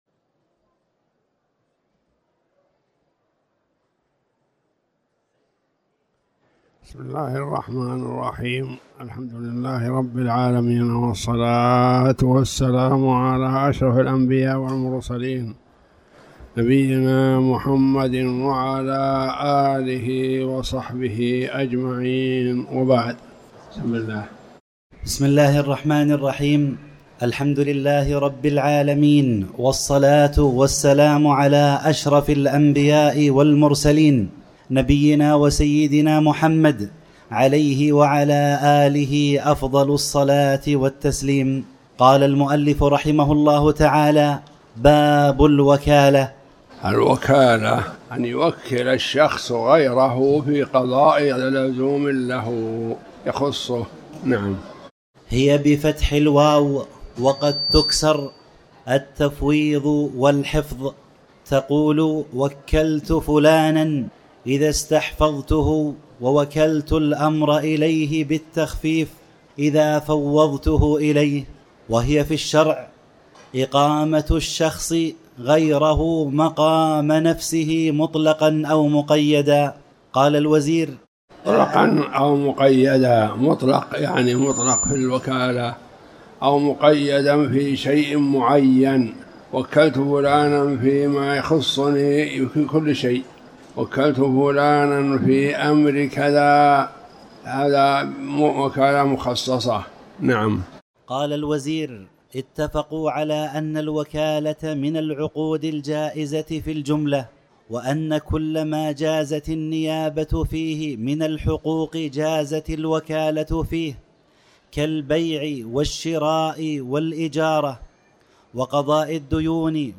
تاريخ النشر ٢١ جمادى الأولى ١٤٤٠ هـ المكان: المسجد الحرام الشيخ